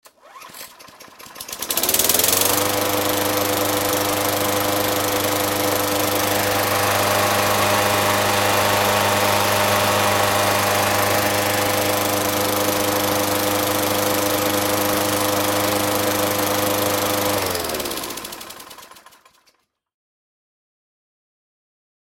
Звук газонокосилки, которая не заводится